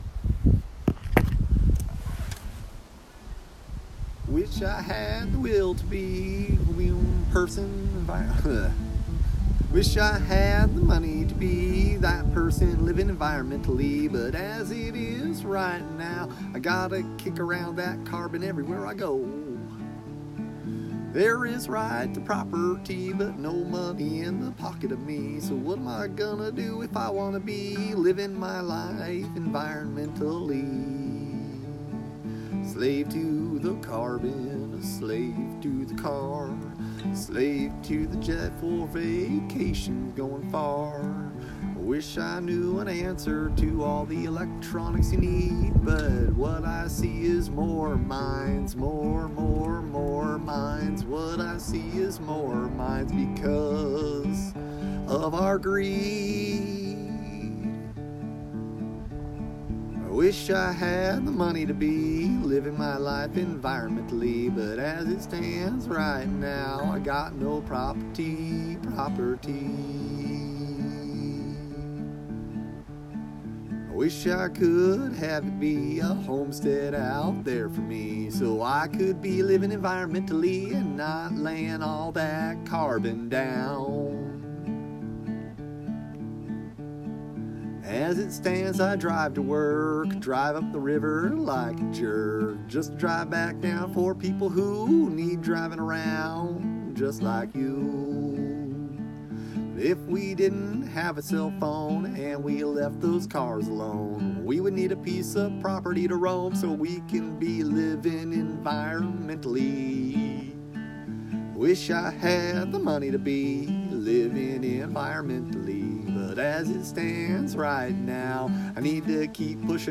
Freestyle Folk [audio]